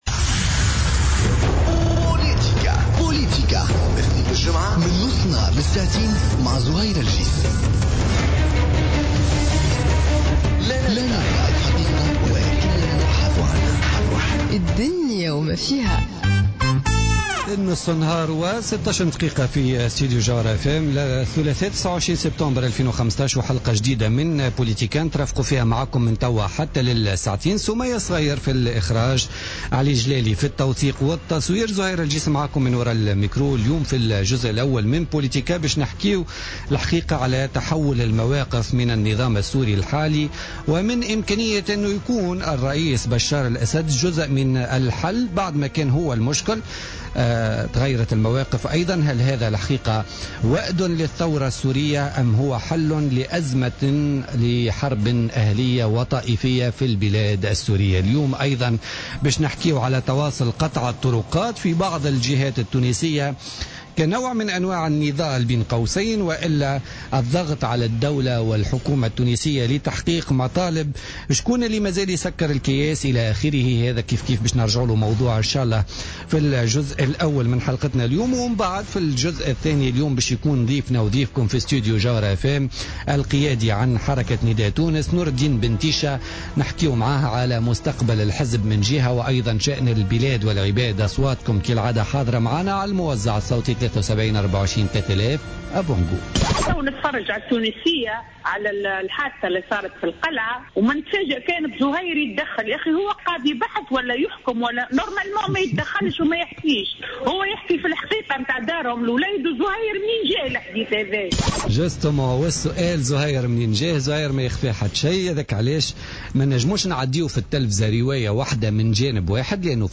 Interview avec Noureddine Ben Ticha